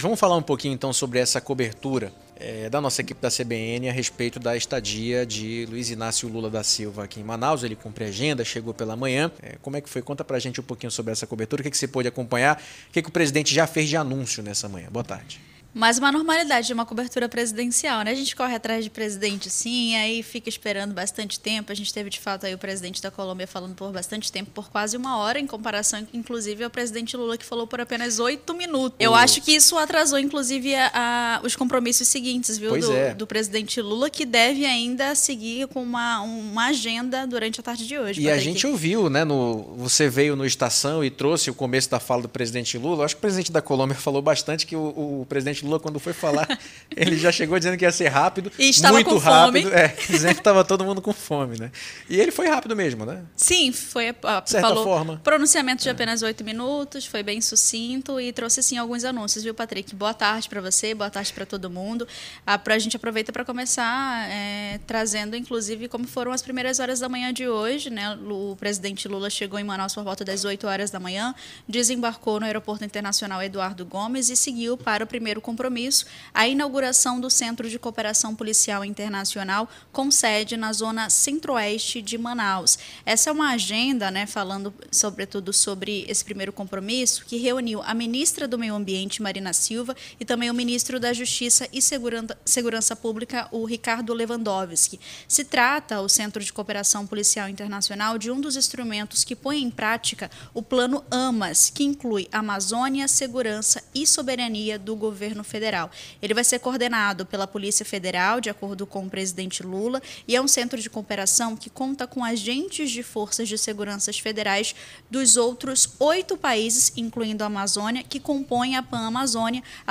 COLETIVA-LULA.mp3